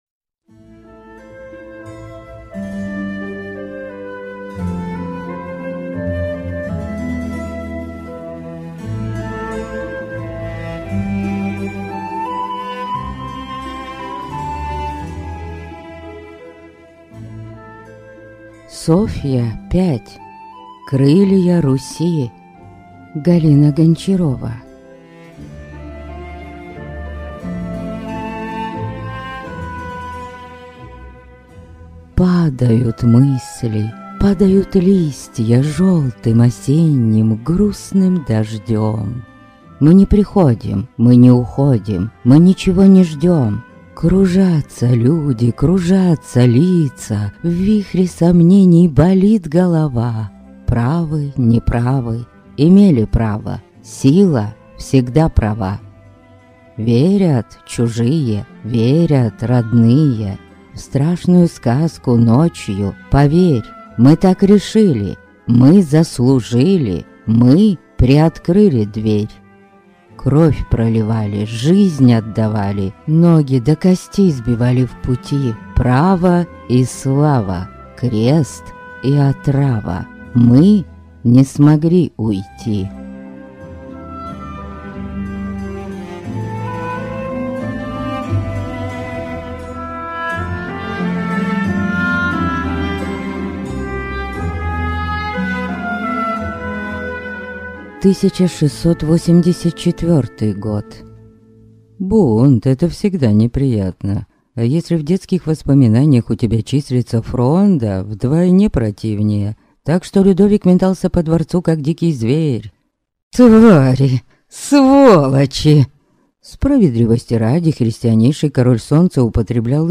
Аудиокнига Азъ есмь Софья. Крылья Руси - купить, скачать и слушать онлайн | КнигоПоиск